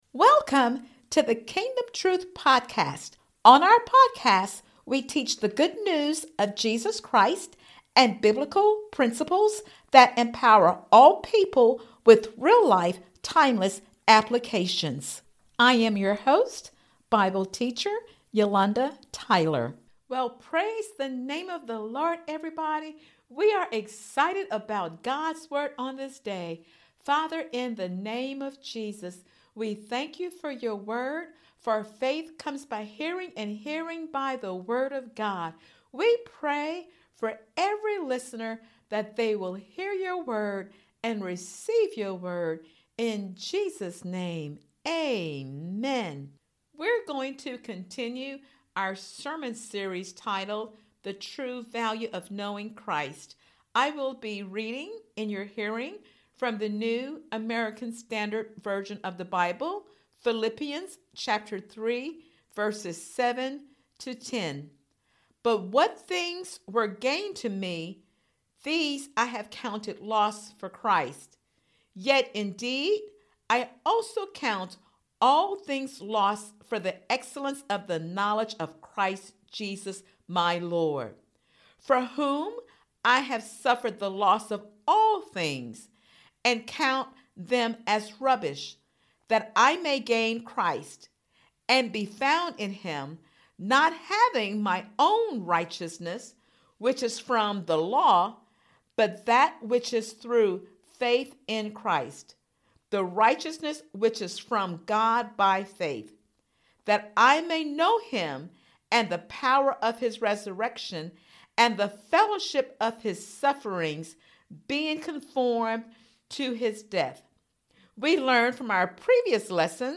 In this beautiful and inspirational teaching from the Word of God, Philippians 3:7-10, the Apostle Paul shares his driving passion to know the Lord , the power of His resurrection, the fellowship of His sufferings, and being conformed to His death.